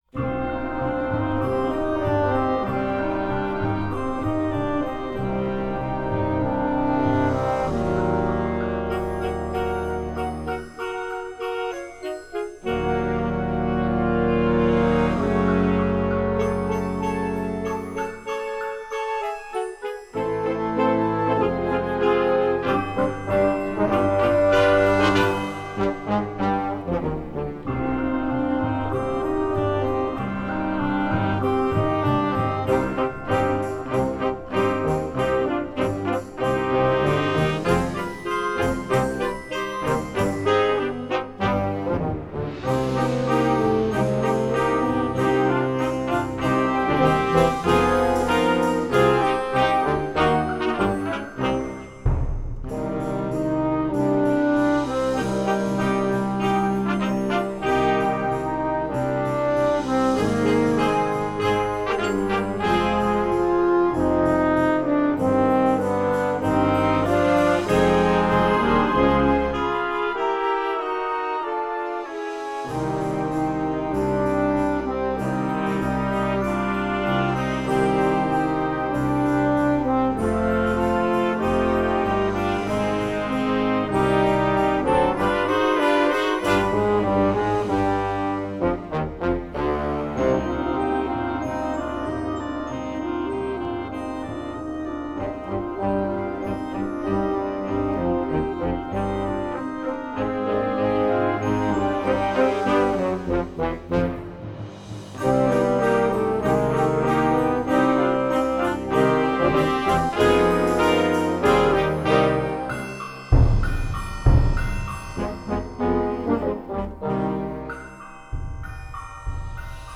Gattung: Weihnachtslied für Jugendblasorchester
Besetzung: Blasorchester